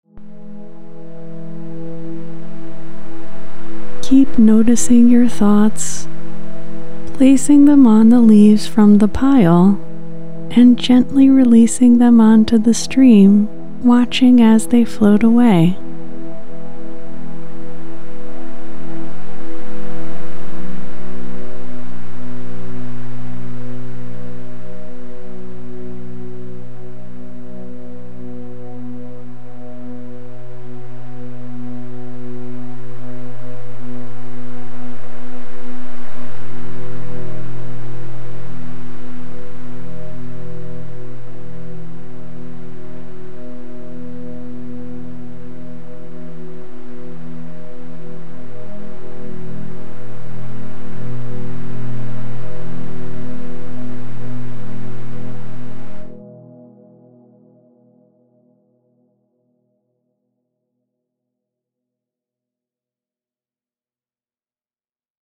This is a mindfulness meditation designed to help you become more objectively aware of your thinking. Guidance will support you in practicing allowing thoughts to come and go, in and out of your awareness.